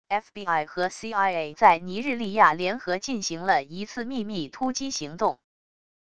FBI和CIA在尼日利亚联合进行了一次秘密突击行动wav音频生成系统WAV Audio Player